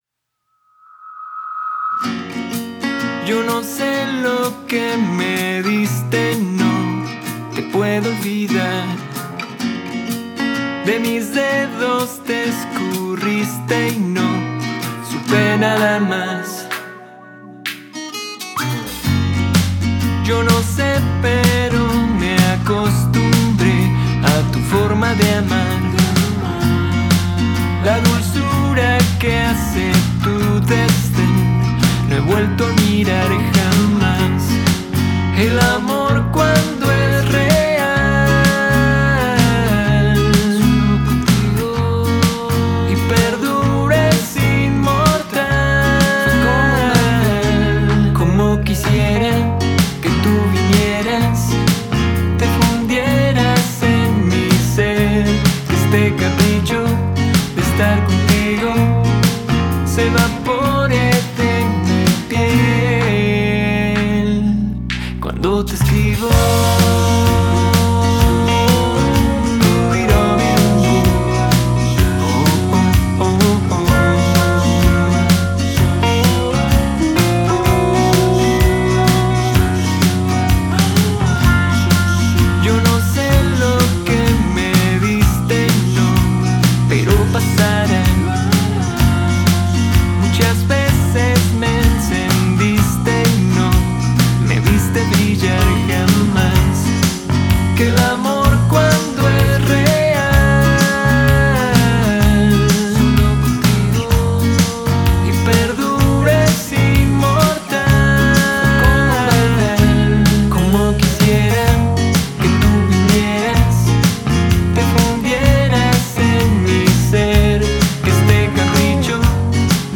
chanteur et compositeur Mexicain.